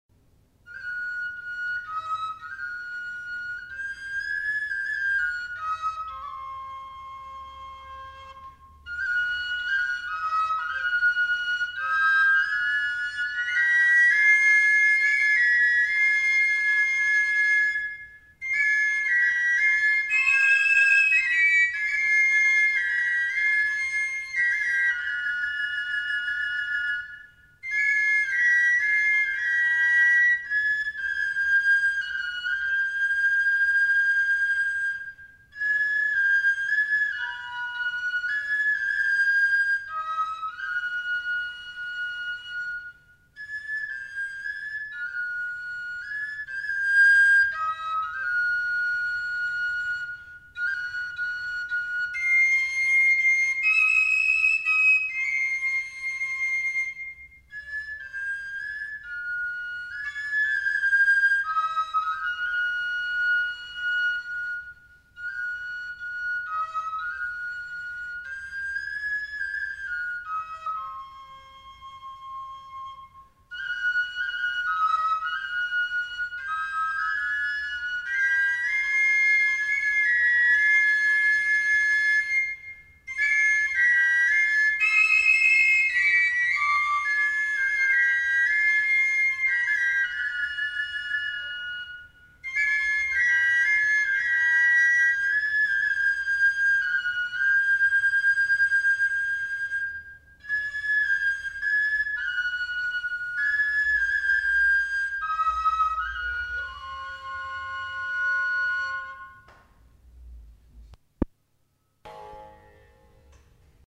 Instrumental. Flûte à trois trous. Béarn
Aire culturelle : Béarn
Lieu : Bielle
Genre : morceau instrumental
Instrument de musique : flûte à trois trous